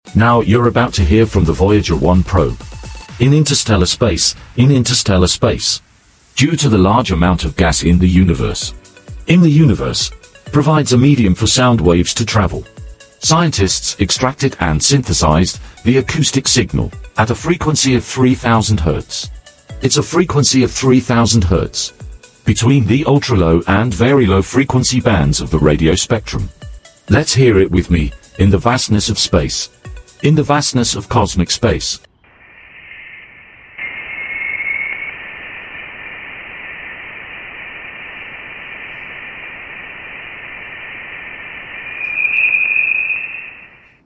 Cosmic eerie sounds captured by sound effects free download
Cosmic eerie sounds captured by Voyager 1 in interstellar space